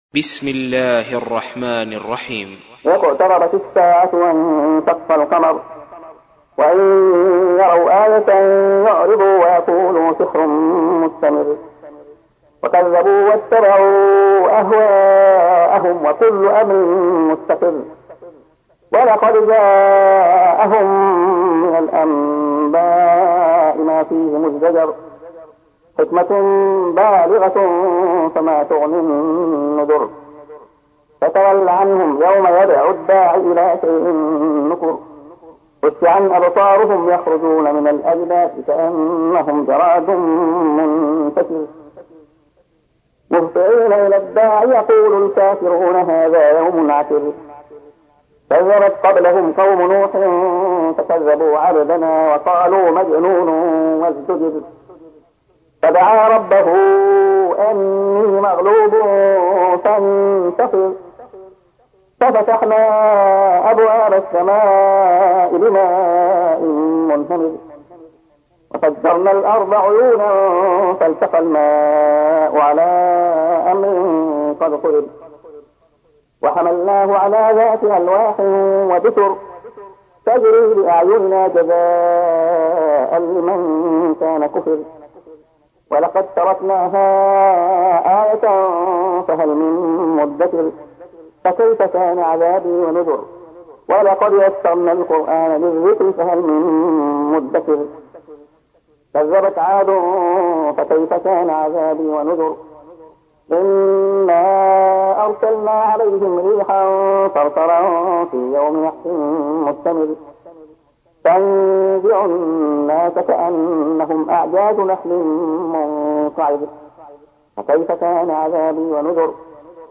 تحميل : 54. سورة القمر / القارئ عبد الله خياط / القرآن الكريم / موقع يا حسين